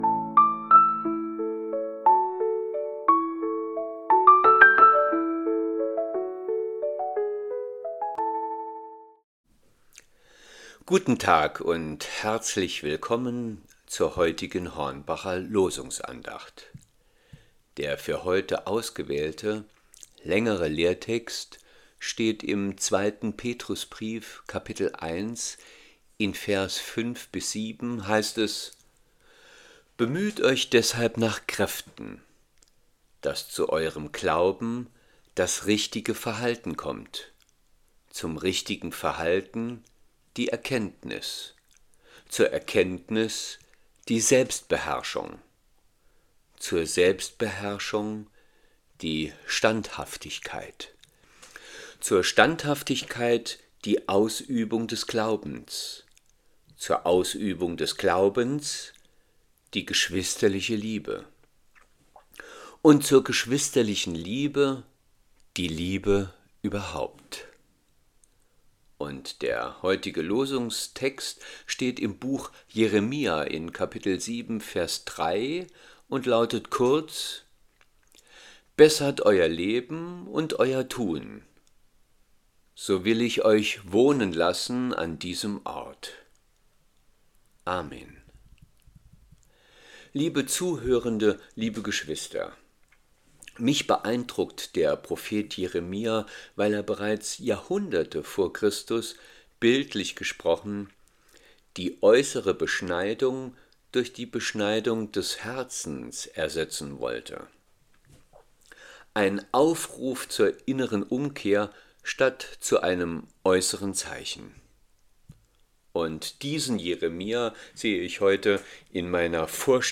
Losungsandacht für Donnerstag, 18.12.2025 – Prot.